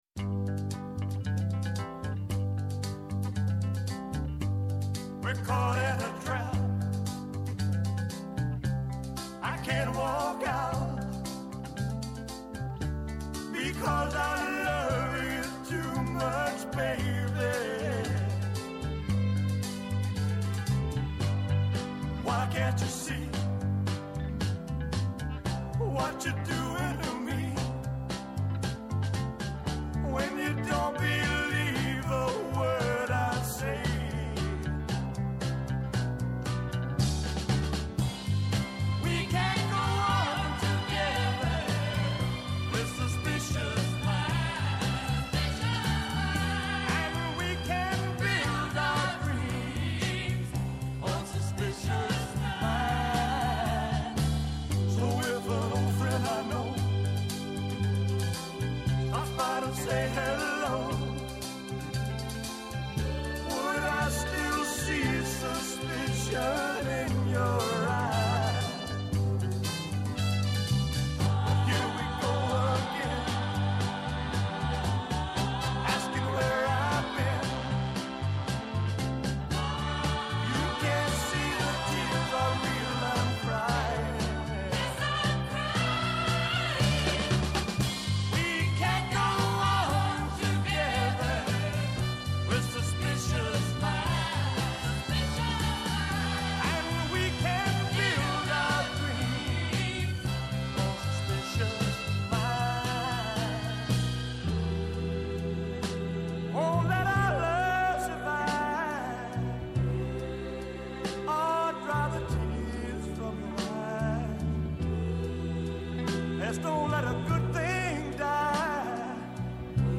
Από Δευτέρα έως Πέμπτη 11 με 12 το μεσημέρι στο Πρώτο Πρόγραμμα.